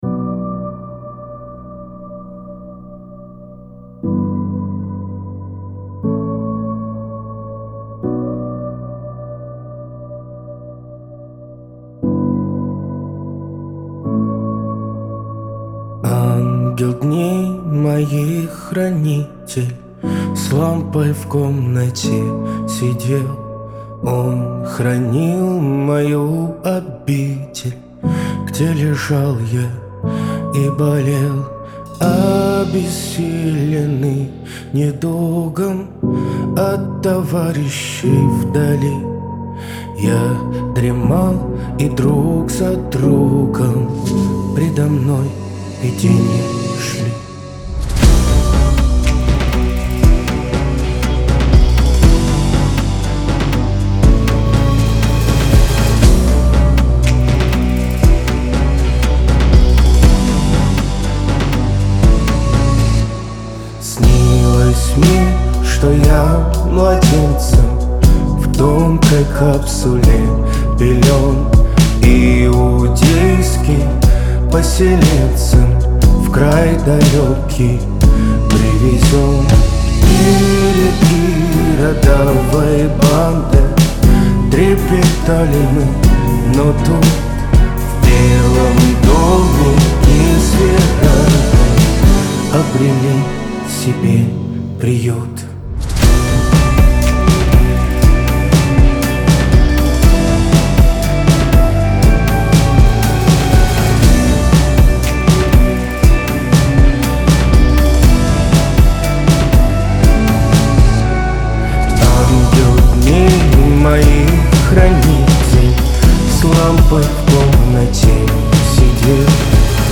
Жанр:Русские новинки / OSTСаундтреки